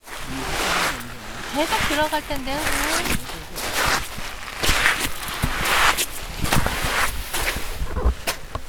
모래걸음.ogg